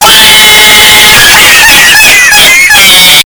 Loud Cat Laughing Sound Effect Download: Instant Soundboard Button
The Loud Cat Laughing sound button is a popular audio clip perfect for your soundboard, content creation, and entertainment.